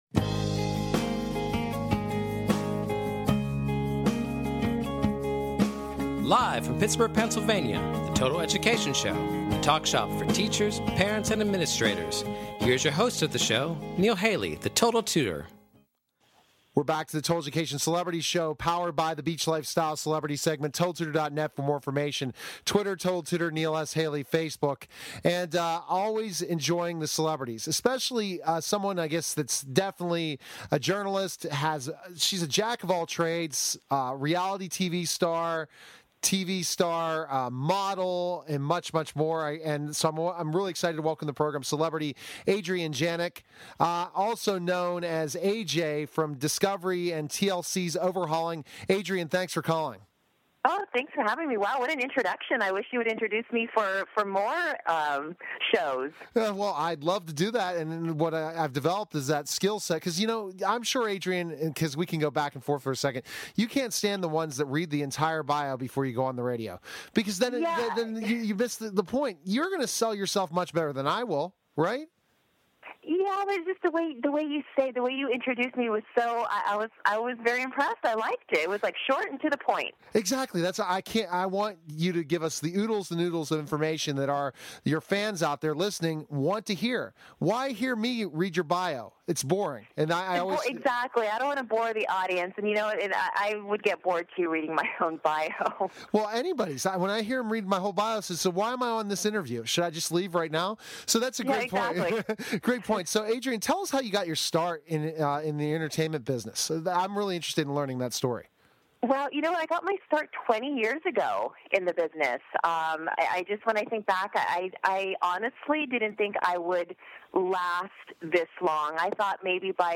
Talk Show Episode, Audio Podcast, Total_Education_Show and Courtesy of BBS Radio on , show guests , about , categorized as